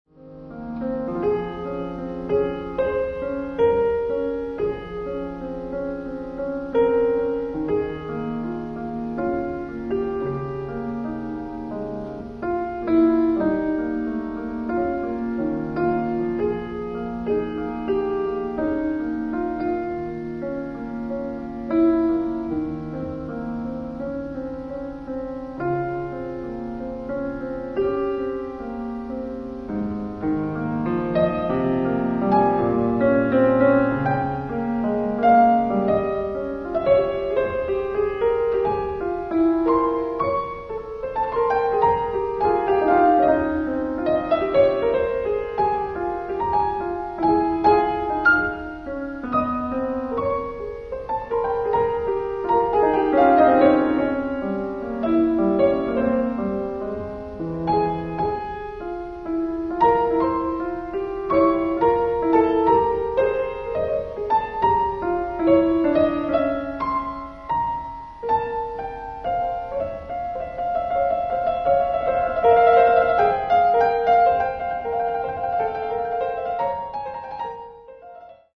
Free Piano MP3